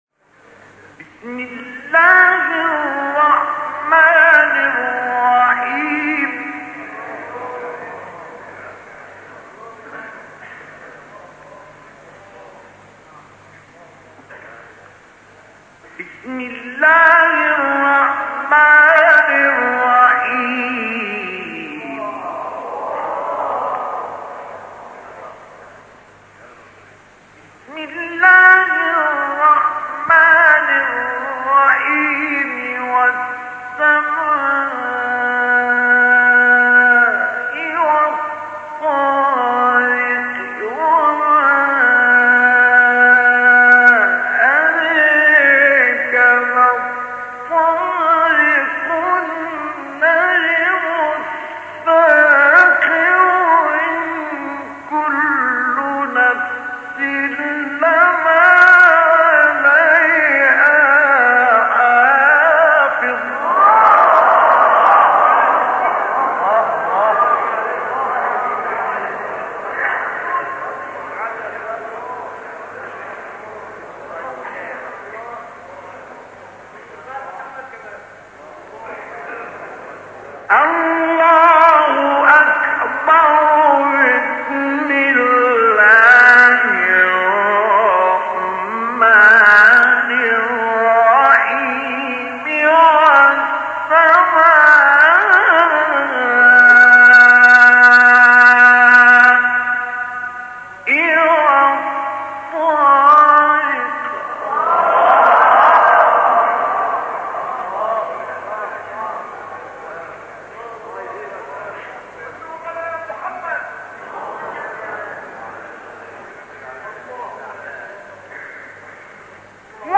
تلاوت زیبای سوره طارق استاد مصطفی اسماعیل | نغمات قرآن | دانلود تلاوت قرآن